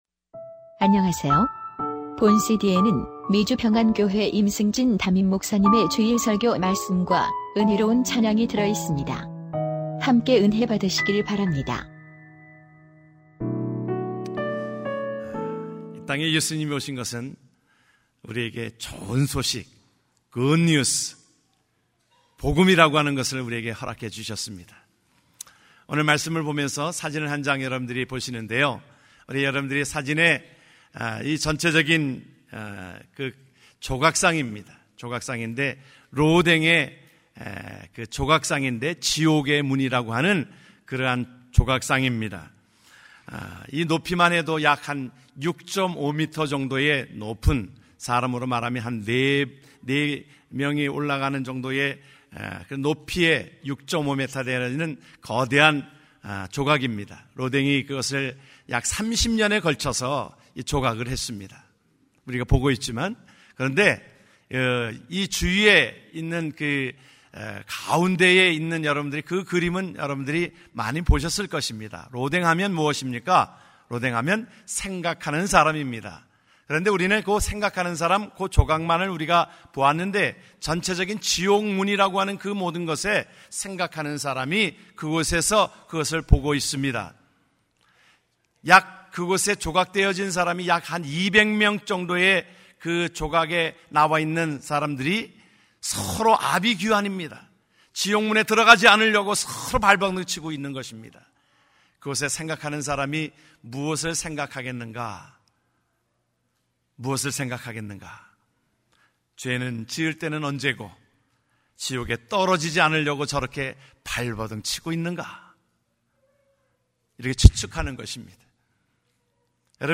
2014년 12월 21일 미주평안교회 주일설교말씀: 좋은 소식(누가복음 2장 1-14절)